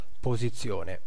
Ääntäminen
IPA : /pəˈzɪʃən/